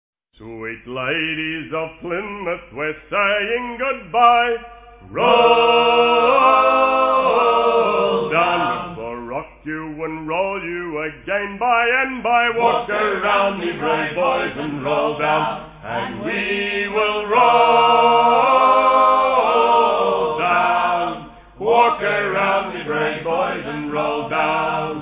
in front of a select audience